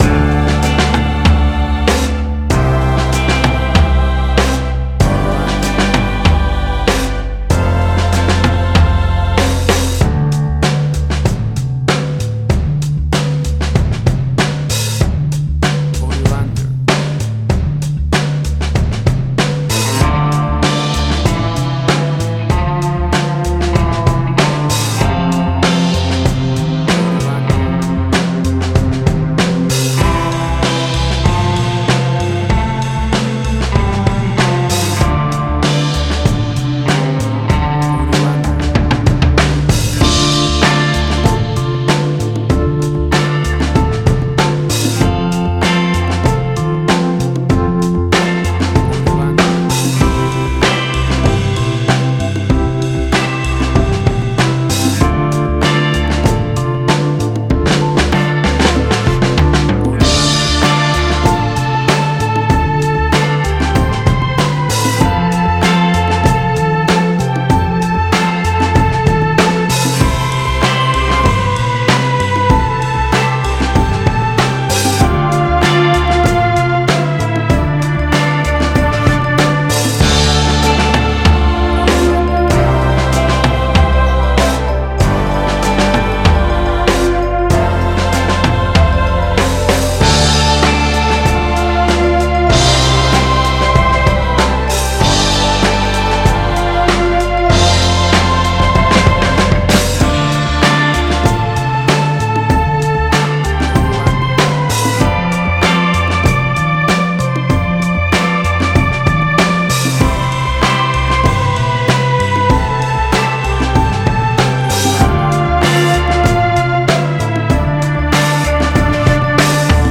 Retro-60s.
Tempo (BPM): 95